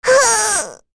Rehartna-Vox_Damage_06.wav